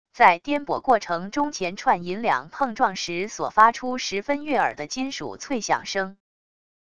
在颠簸过程中钱串银两碰撞时所发出十分悦耳的金属脆响声wav音频